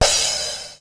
• Roomy Drum Crash Sound E Key 05.wav
Royality free crash sound tuned to the E note. Loudest frequency: 5329Hz
roomy-drum-crash-sound-e-key-05-KLZ.wav